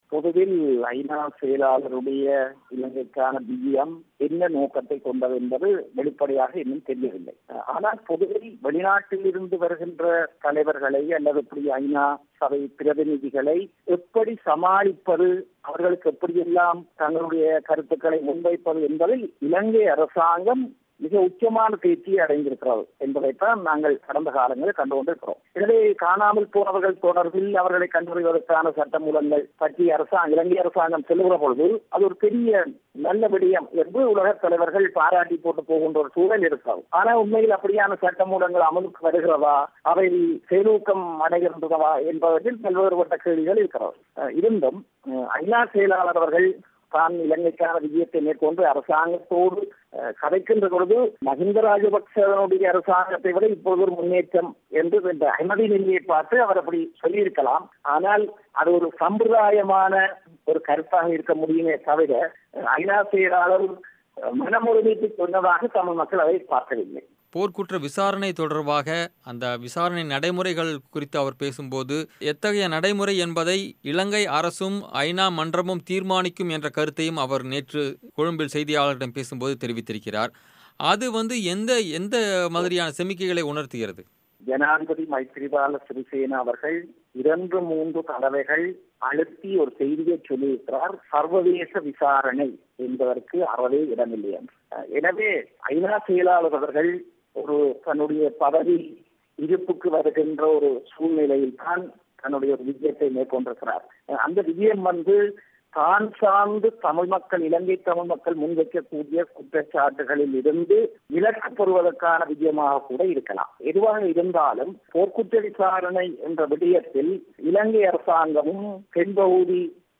ஐ.நா. செயலாளர் நாயகம் பான் கி மூனின் மூன்று நாள் இலங்கை பயணம் குறித்த பேட்டி